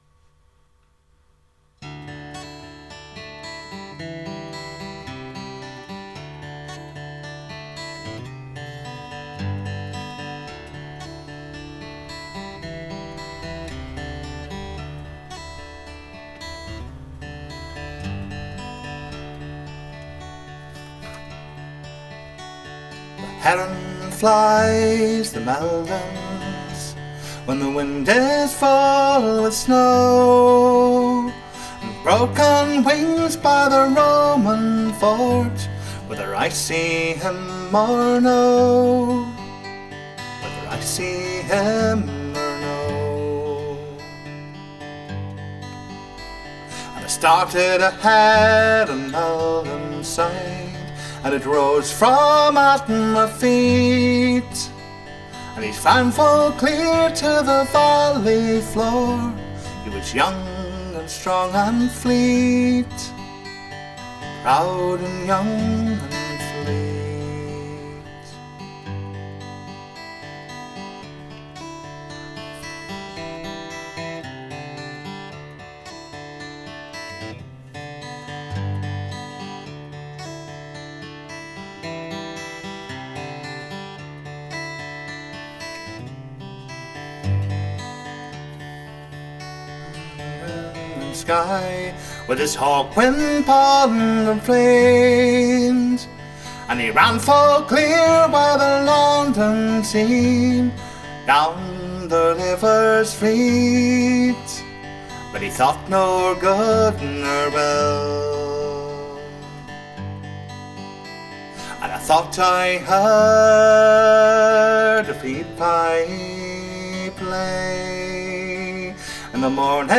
a never before released demo version